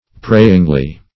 prayingly - definition of prayingly - synonyms, pronunciation, spelling from Free Dictionary Search Result for " prayingly" : The Collaborative International Dictionary of English v.0.48: Prayingly \Pray"ing*ly\, adv.